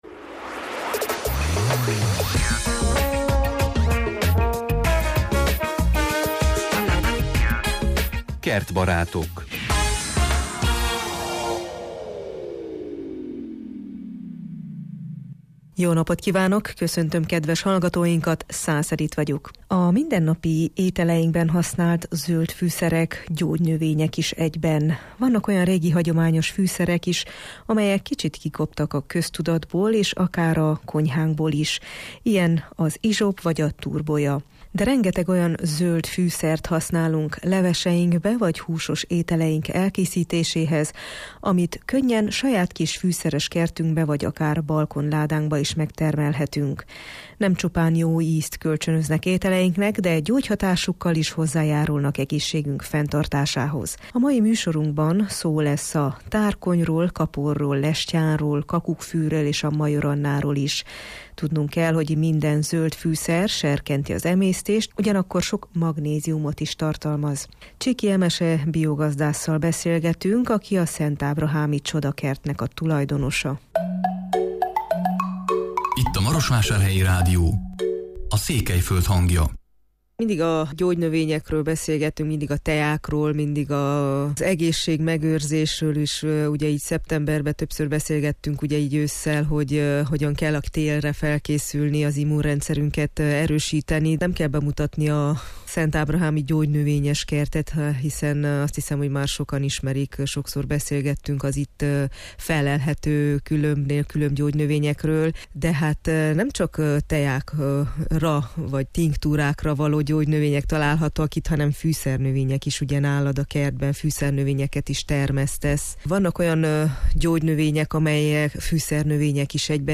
bió gazdásszal beszélgetünk